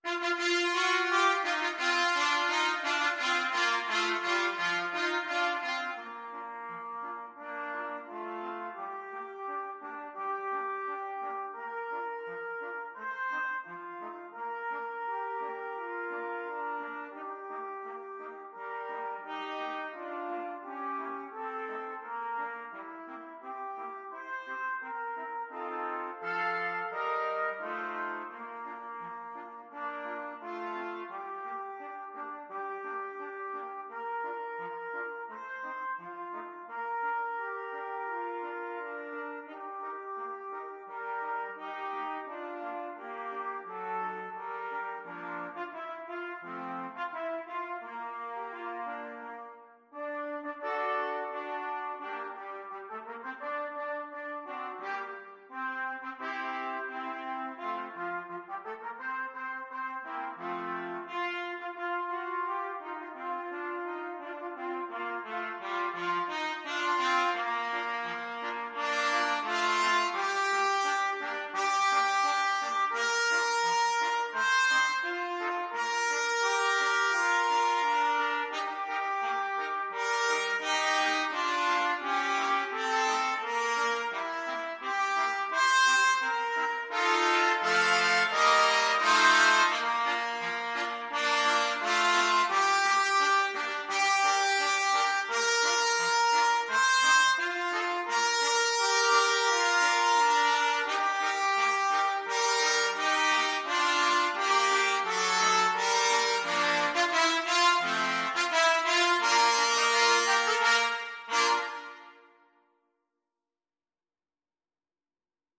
Free Sheet music for Trumpet Quartet
Trumpet 1Trumpet 2Trumpet 3Trumpet 4
March Tempo - Moderato = c.86
Bb major (Sounding Pitch) C major (Trumpet in Bb) (View more Bb major Music for Trumpet Quartet )
2/2 (View more 2/2 Music)
anchors_aweigh_4TPT.mp3